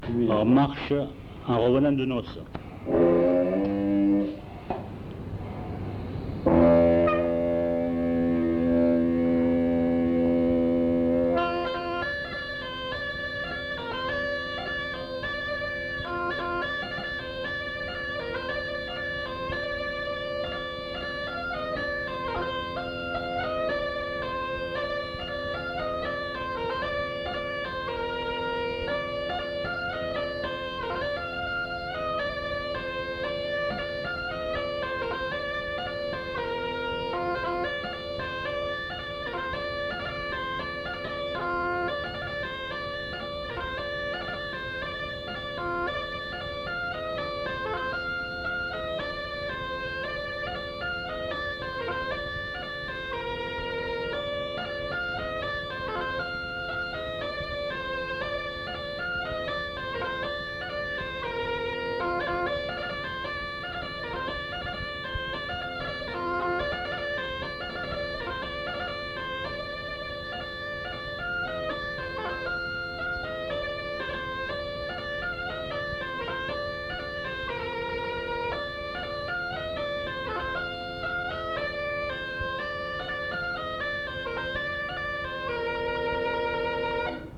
Aire culturelle : Cabardès
Genre : morceau instrumental
Instrument de musique : craba